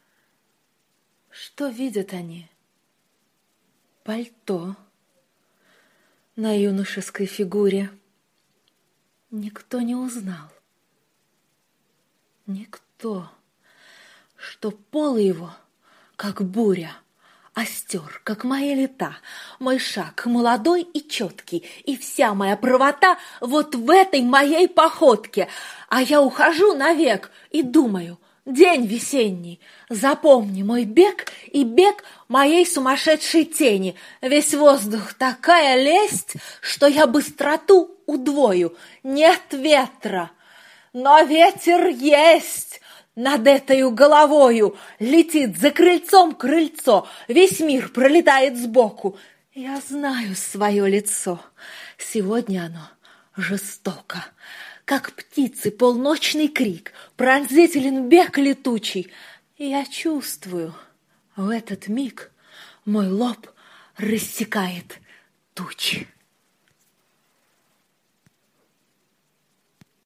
1. «Чтец – Е. Симонова – М. Цветаева. Что видят они? – Пальто!» /